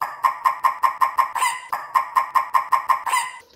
cacarejando.mp3